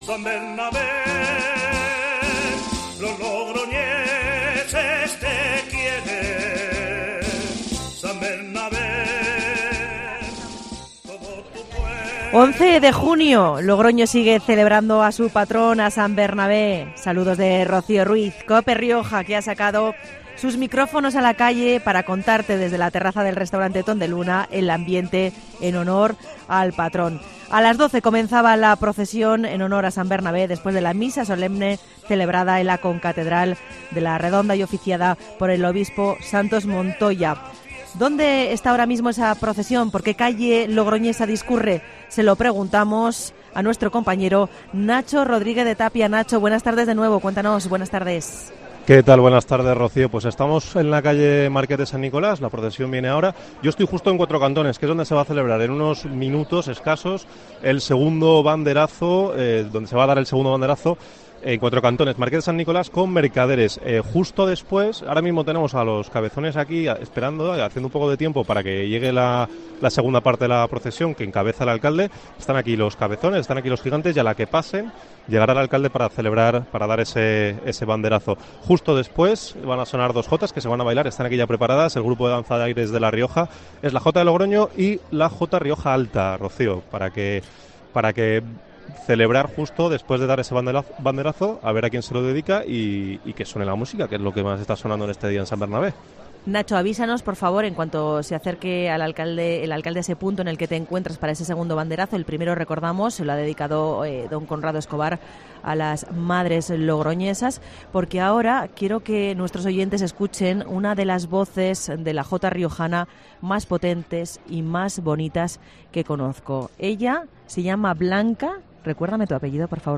En COPE Rioja te hemos contado en directo el recorrido de esta procesión en honor a San Bernabé y también hemos estado en el entorno de la Muralla del Revellín para contarte este reparto del pez, pan y vino en directo. Los cofrades nos han contado cómo viven este día y los logroñeses cómo cumplen con la tradición.